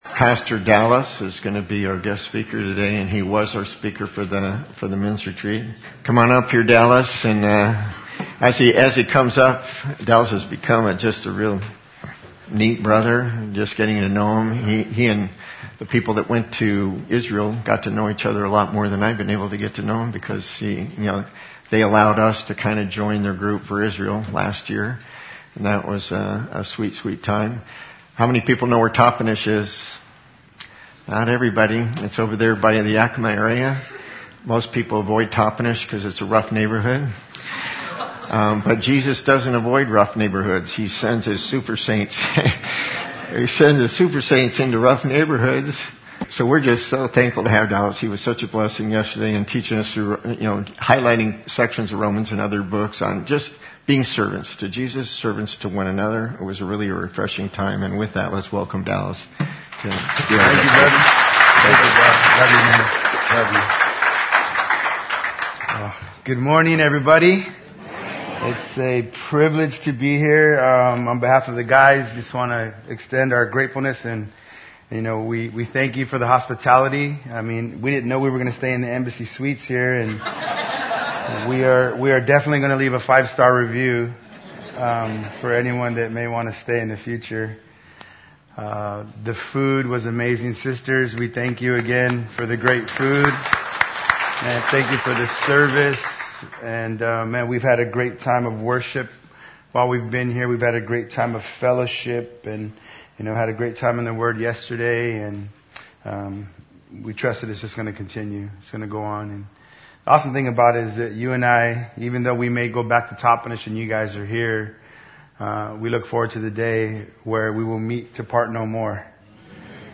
In today’s teaching our Guest Speaker